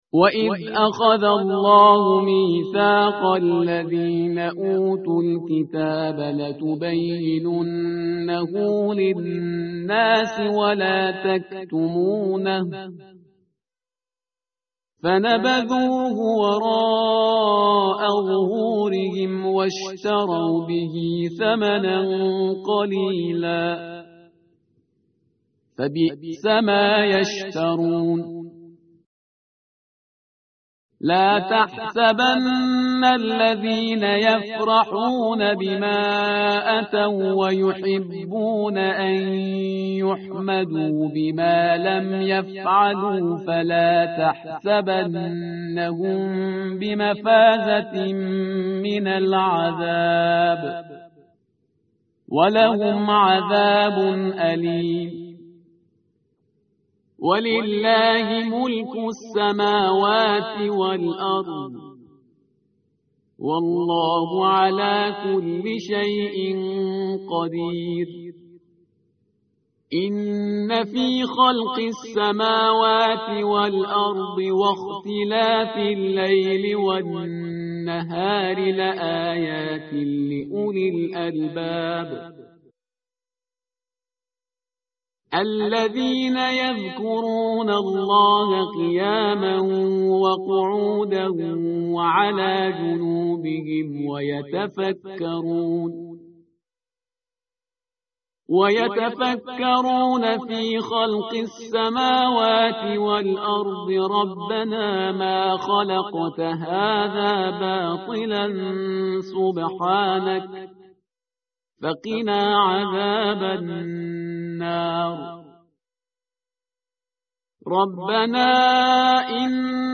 متن قرآن همراه باتلاوت قرآن و ترجمه
tartil_parhizgar_page_075.mp3